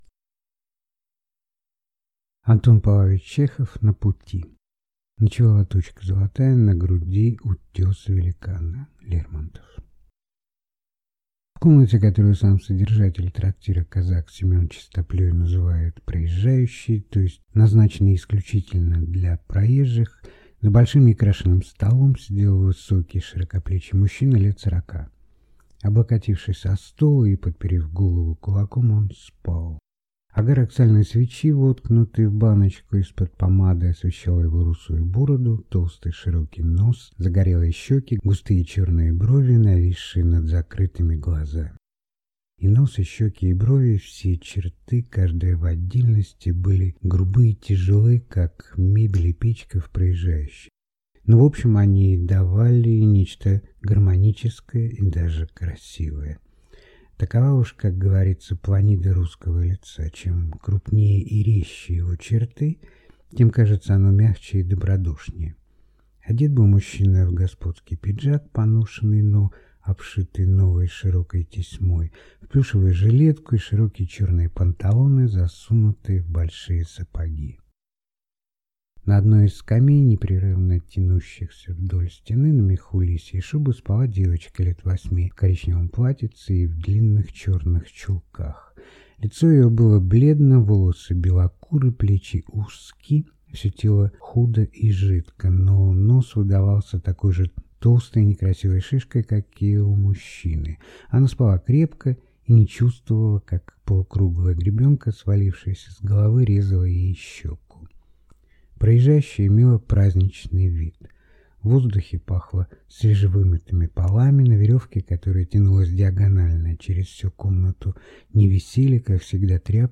Аудиокнига На пути | Библиотека аудиокниг